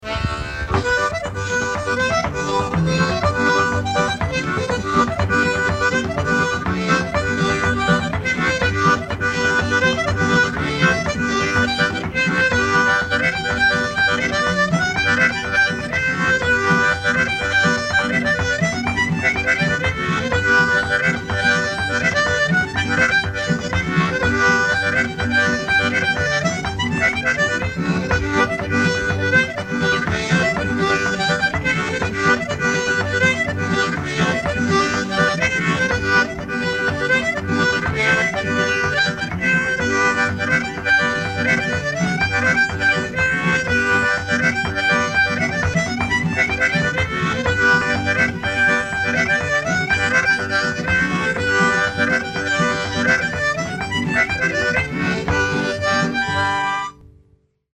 Money Musk (harmonica solo) | Bowing Down Home